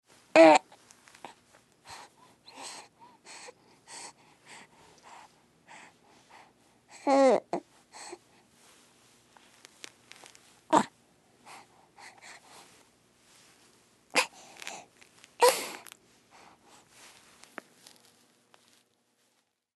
Звуки грудного ребёнка
Звук который издает только что родившийся малыш (в роддоме) (00:20)
в роддоме